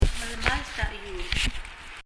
melemalt a chiul [mɛlɛməlt ə ? iul]